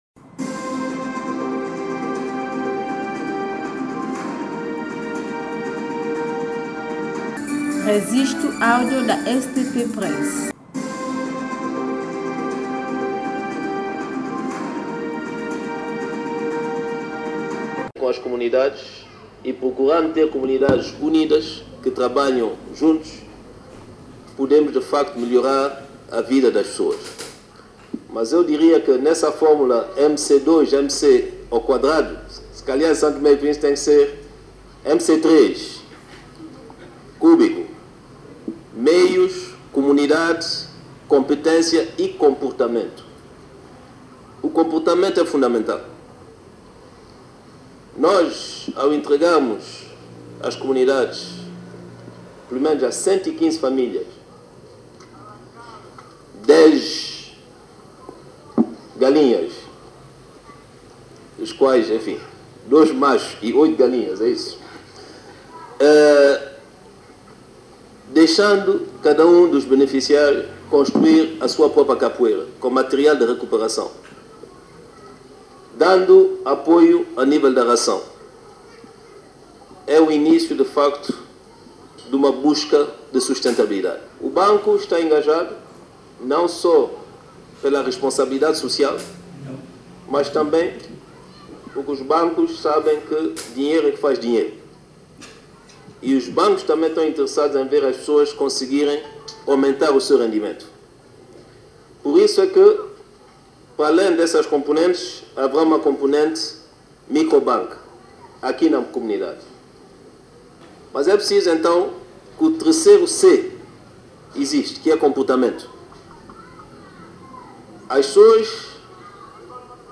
Declaração do Primeiro-Ministro, Patrice Trovoada